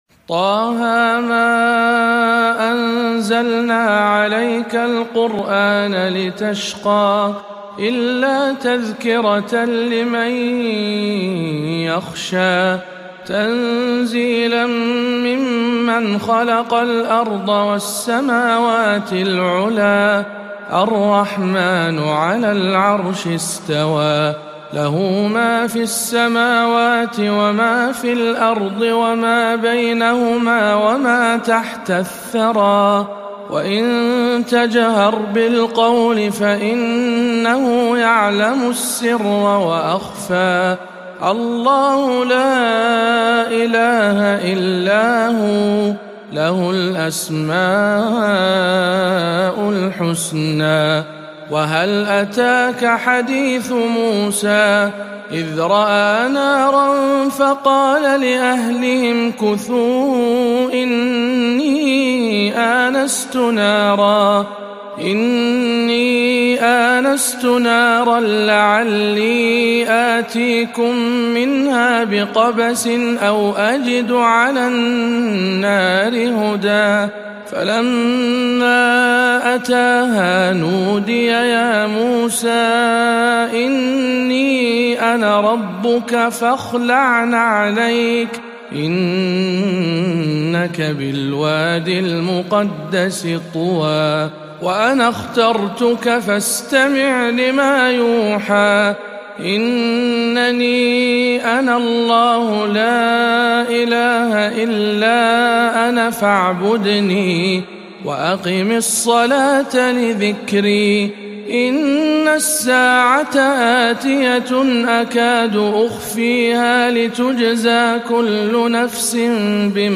سورة طه بمسجد المحمدية الغربية بالرياض